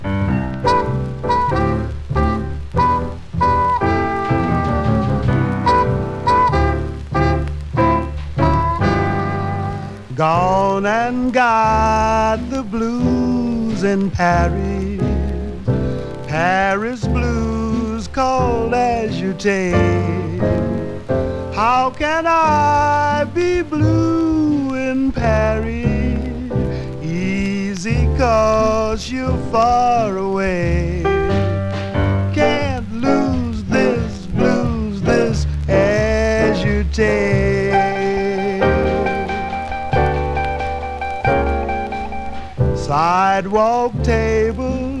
黒光りするような魅力溢れる演奏、素敵さと洒落た印象も含んだ好内容。
Jazz, Rhythm & Blues　USA　12inchレコード　33rpm　Mono